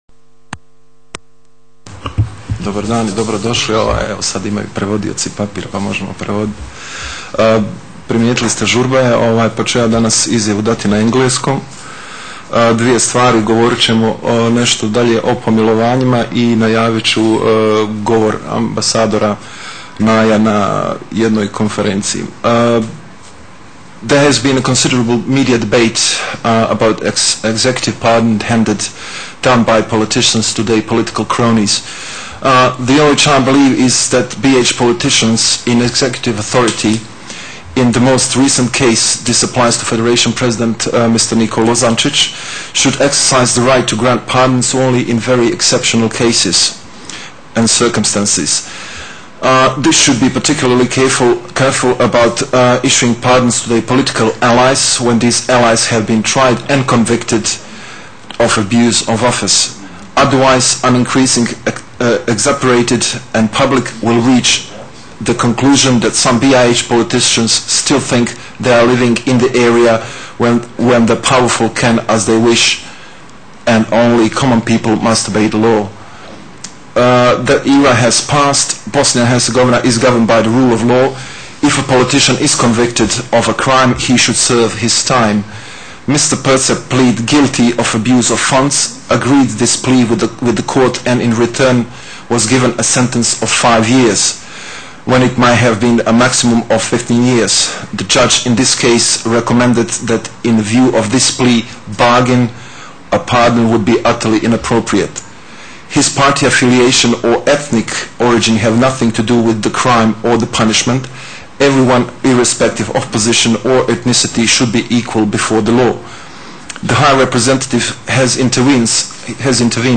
Transcript of the International Agencies’ Joint Press Conference